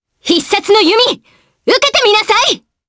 Selene-Vox_Skill5_jp.wav